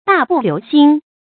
大步流星 注音： ㄉㄚˋ ㄅㄨˋ ㄌㄧㄨˊ ㄒㄧㄥ 讀音讀法： 意思解釋： 流星：比喻走得快。